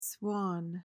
PRONUNCIATION: (swan) MEANING: noun:1.